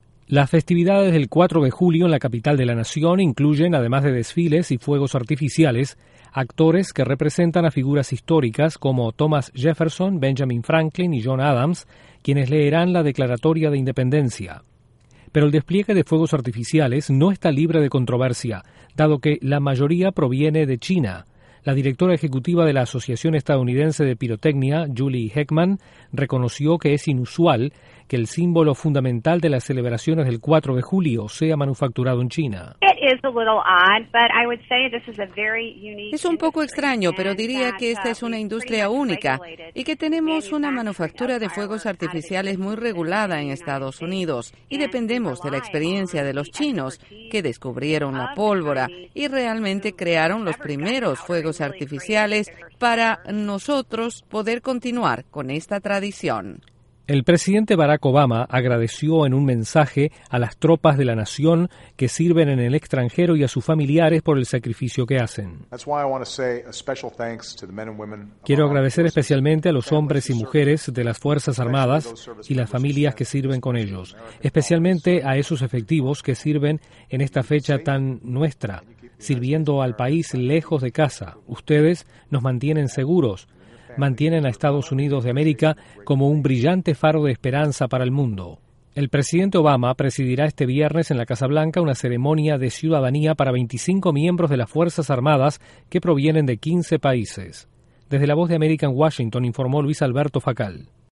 Estados Unidos celebra este viernes 238 años de la independencia con diferentes actos. Detalles desde La Voz de América en Washington